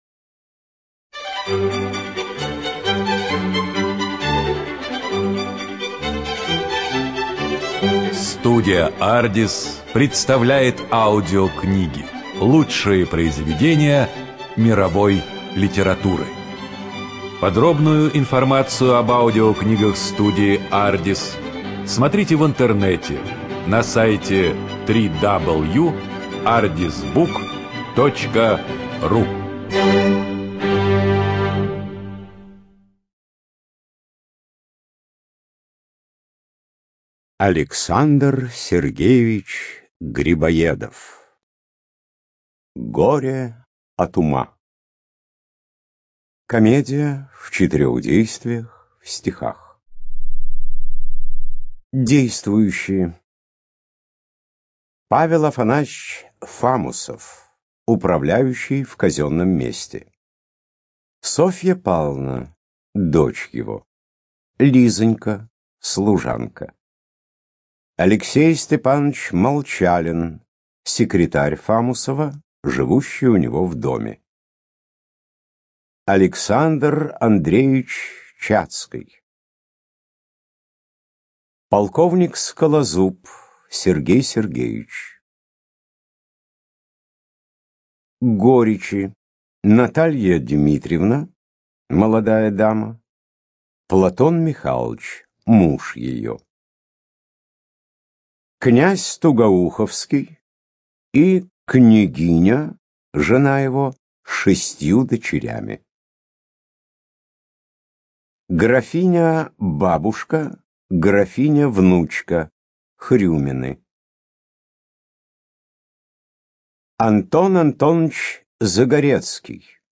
ЖанрДраматургия
Студия звукозаписиАрдис